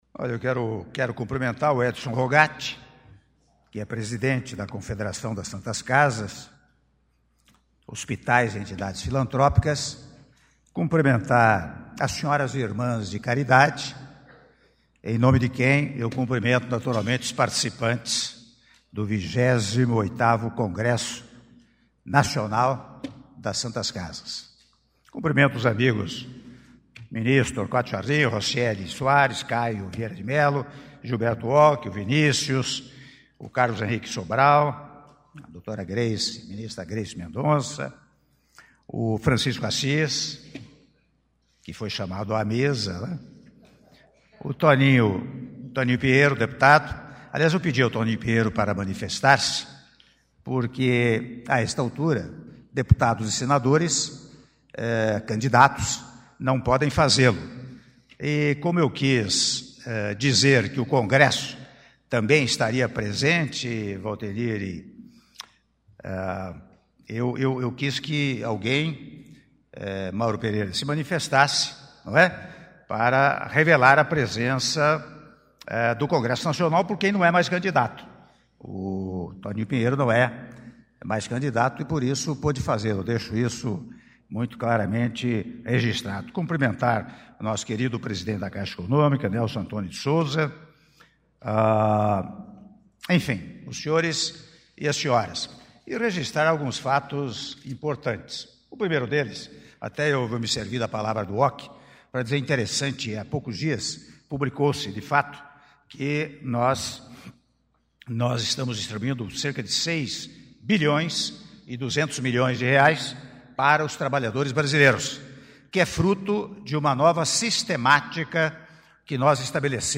Áudio do Discurso do Presidente da República, Michel Temer, durante Cerimônia de assinatura de MP que abre linha de crédito para Santas Casas e Hospitais Filantrópicos -Brasília/DF- (08min23s)